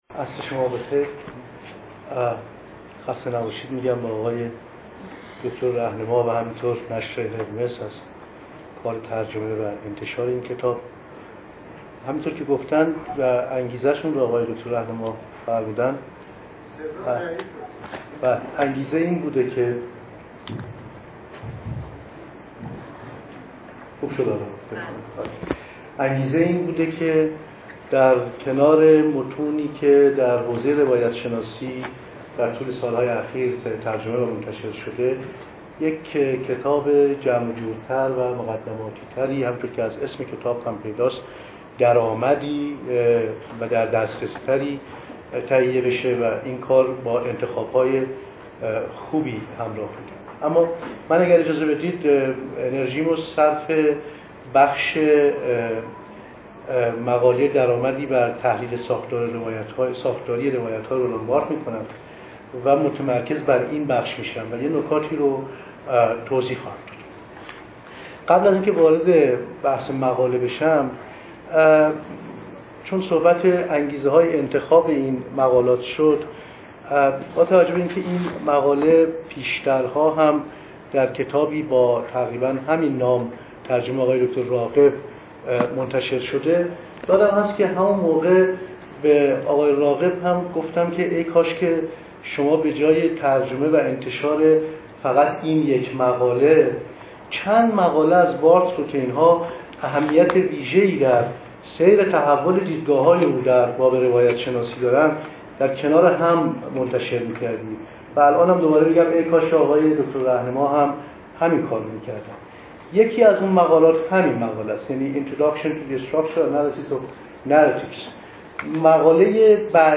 نقد کتاب